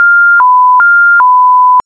scanner_beep.b281c27f.ogg